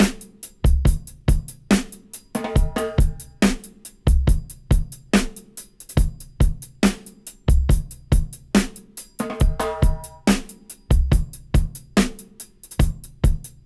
70-DRY-06.wav